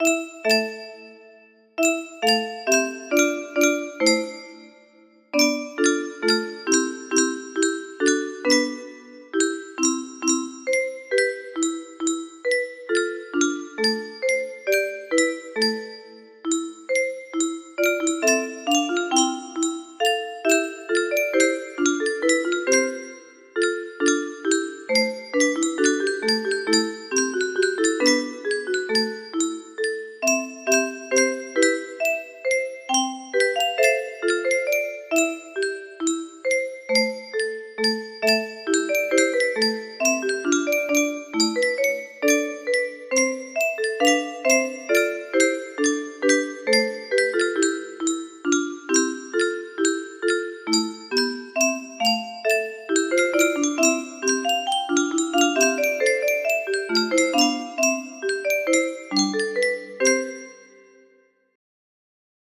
Ye make me spill my ale! music box melody